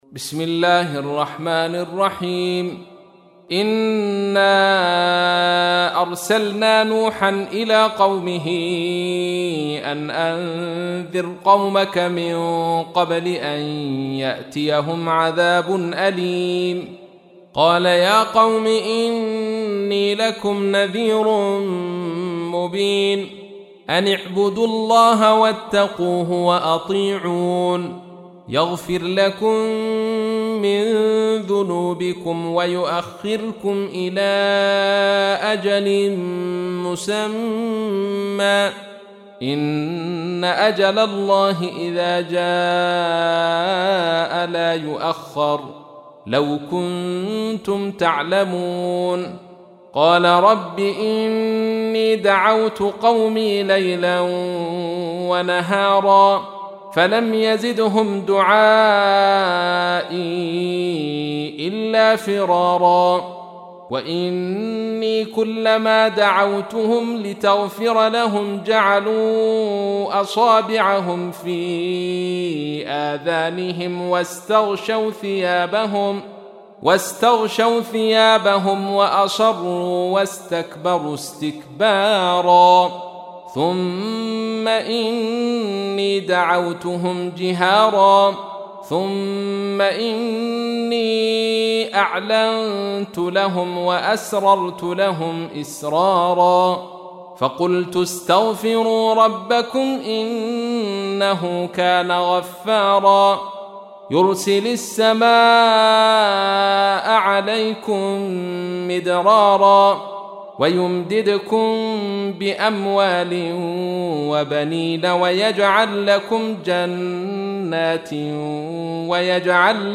تحميل : 71. سورة نوح / القارئ عبد الرشيد صوفي / القرآن الكريم / موقع يا حسين